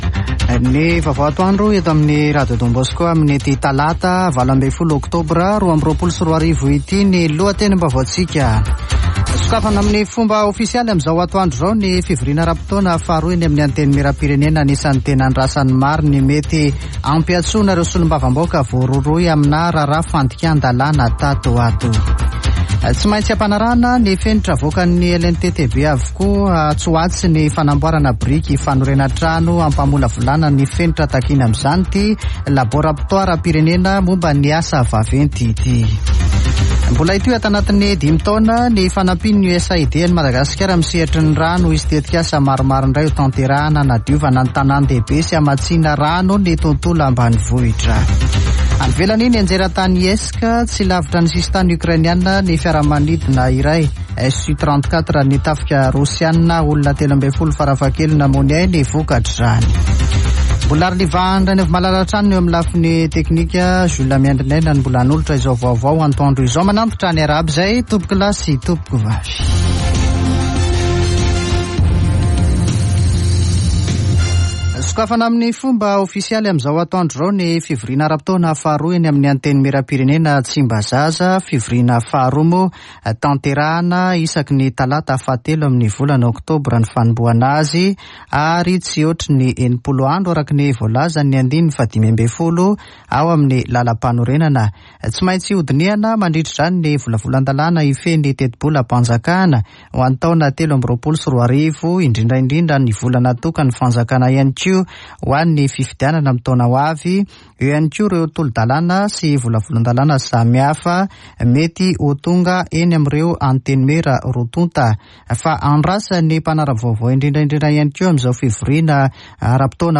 [Vaovao antoandro] Talata 18 ôktôbra 2022